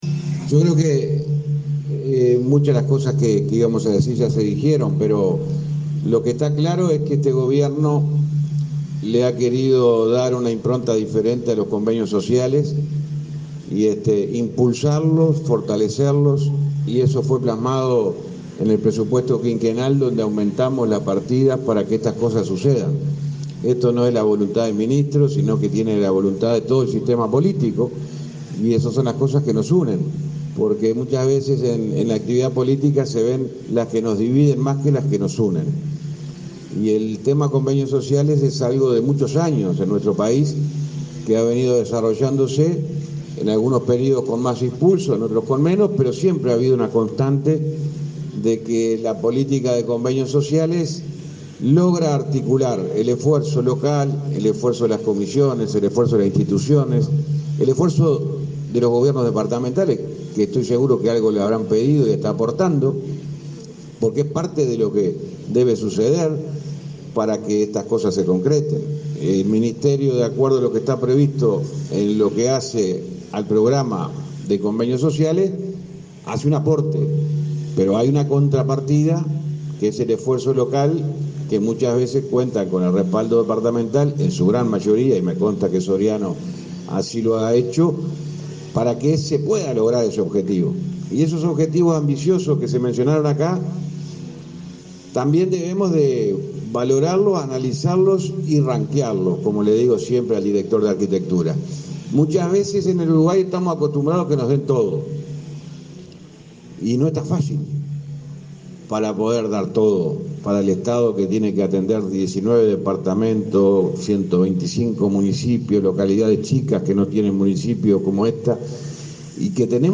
Palabras del ministro de Transporte, José Luis Falero
Palabras del ministro de Transporte, José Luis Falero 06/05/2022 Compartir Facebook X Copiar enlace WhatsApp LinkedIn El ministro de Transporte, José Luis Falero, firmó, este viernes 6 en la localidad de Egaña, departamento de Soriano, un convenio con el hogar de ancianos Don Joaquín para la segunda etapa de la construcción del centro diurno.